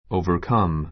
overcome ouvə r kʌ́m オウヴァ カ ム 動詞 三単現 overcomes ouvə r kʌ́mz オウヴァ カ ムズ 過去形 overcame ouvə r kéim オウヴァ ケ イ ム 過去分詞 overcome -ing形 overcoming ouvə r kʌ́miŋ オウヴァ カ ミン ぐ ⦣ 原形と過去分詞が同じ形であることに注意.